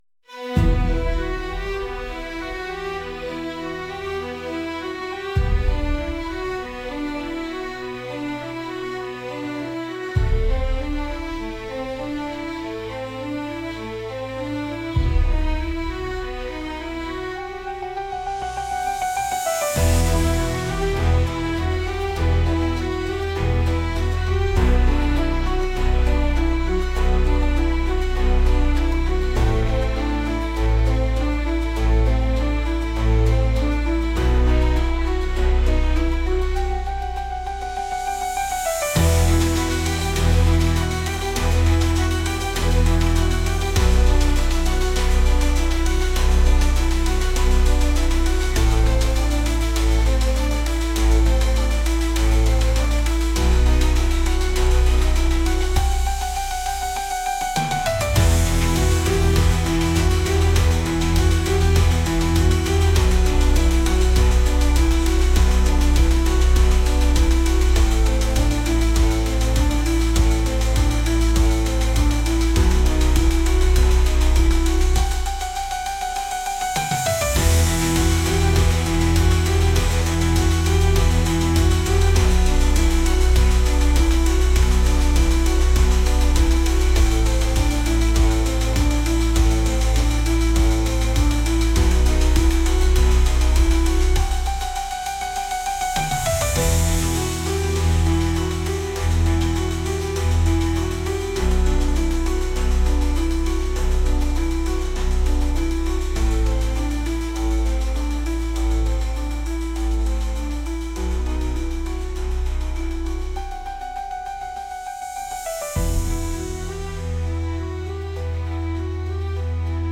「大人な雰囲気」